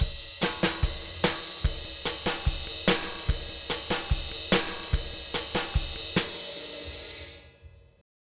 Twisting 2Nite 3 Drumz.wav